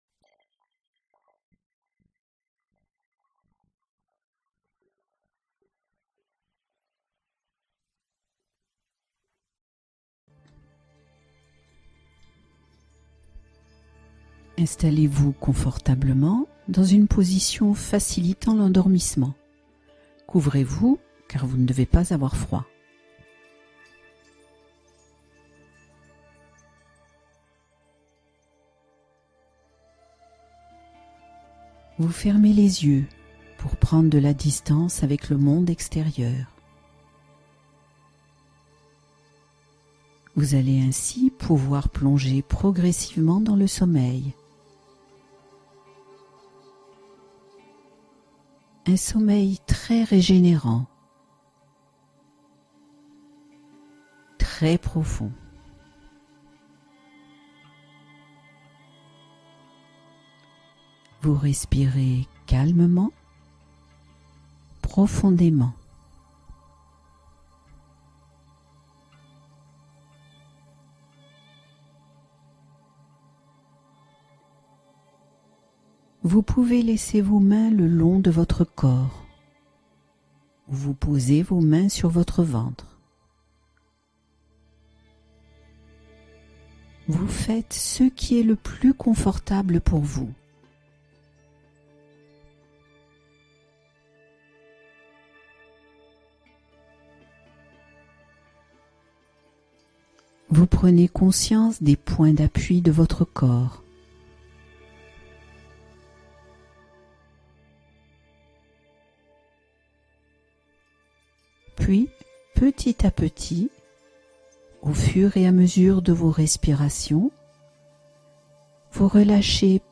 Angoisses calmées en 20 minutes : l'auto-hypnose d'urgence à écouter en cas de crise